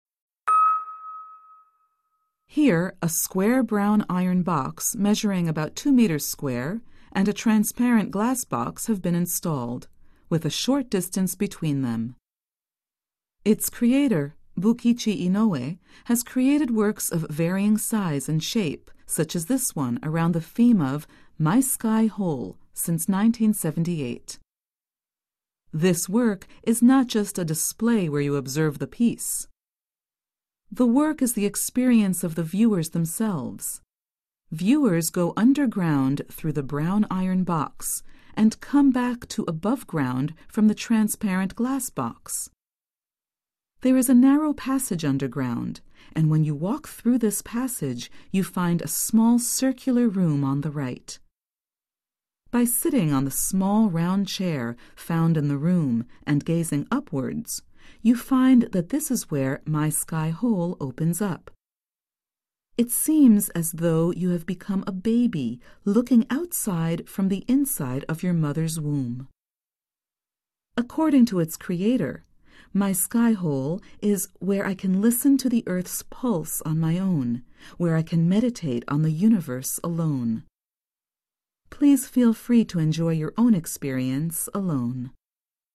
THE HAKONE OPEN-AIR MUSEUM - Audio Guide - Bukichi Inoue my sky hole 79 peephole on the sky 1979